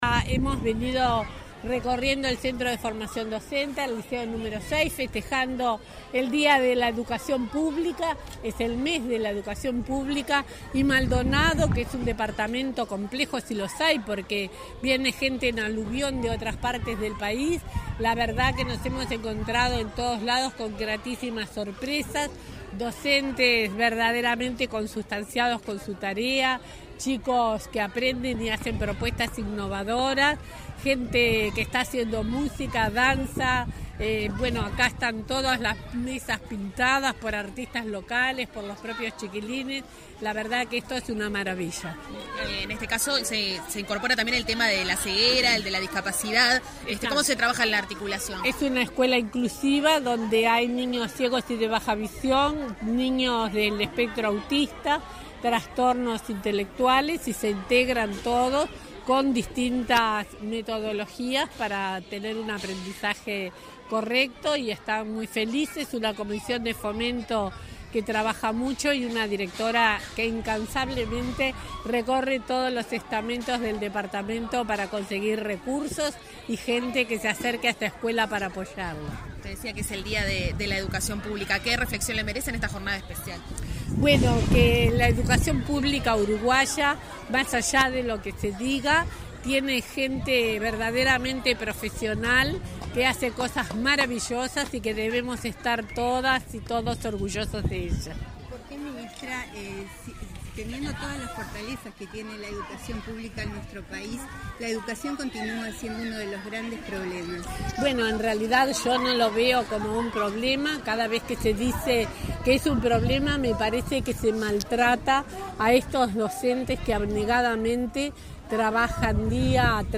En ese contexto, dijo a la prensa que “la educación pública uruguaya tiene gente profesional que hace cosas maravillosas”. “La escuela pública permite la integración de todos y tiene docentes que ingresan y ascienden por concurso”, agregó.